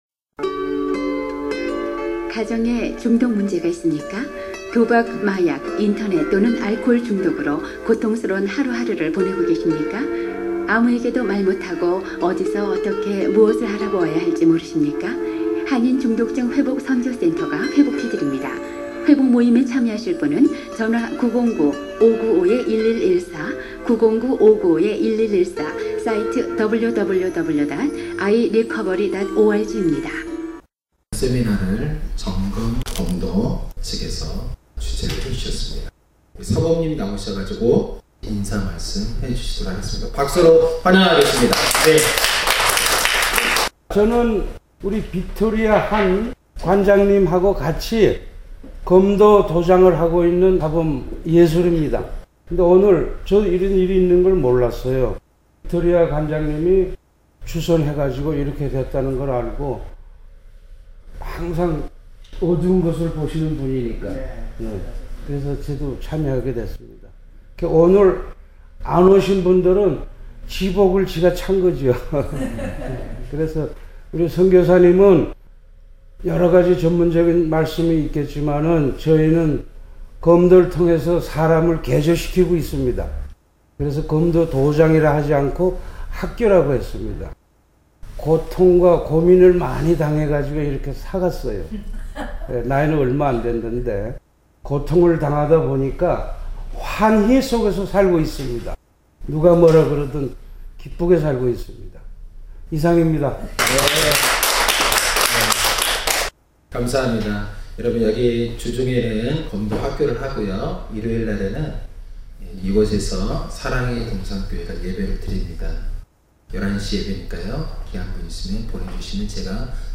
마리화나 남용 예방 - 부모교실 강연내용 > 최선의 중독증 치유는 예방 | 한국어 중독증 치유 방송국